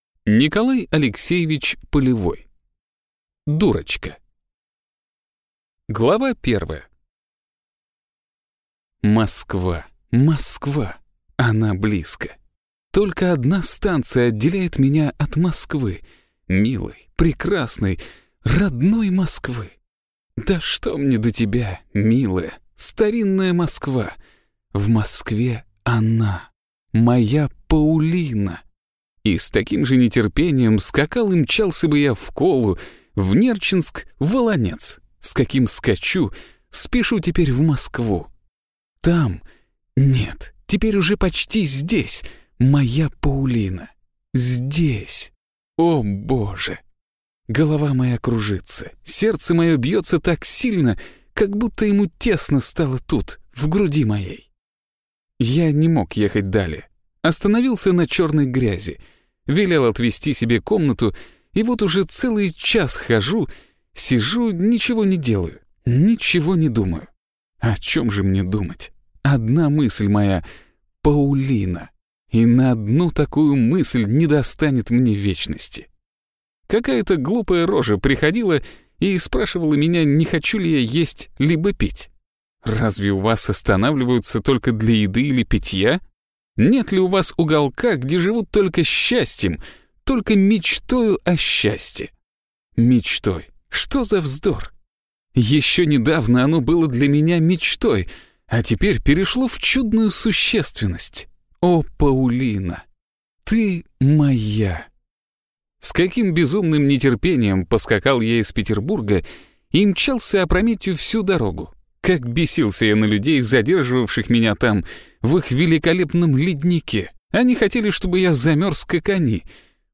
Аудиокнига Дурочка | Библиотека аудиокниг